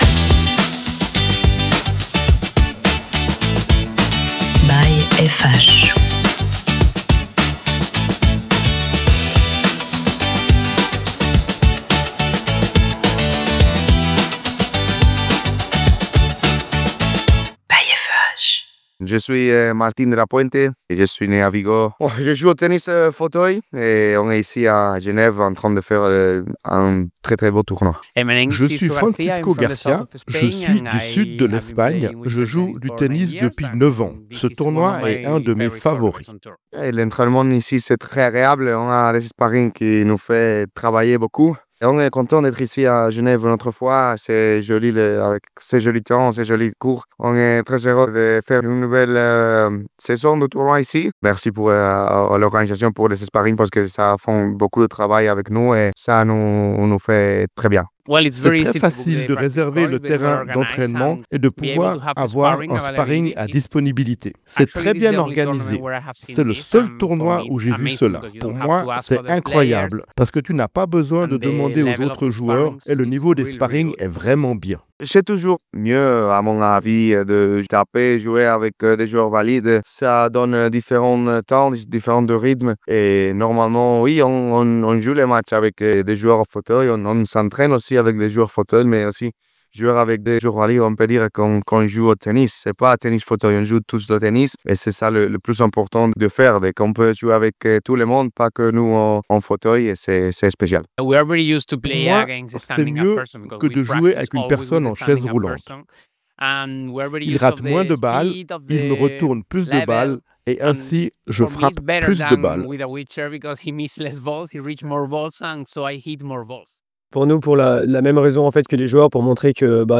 Interview :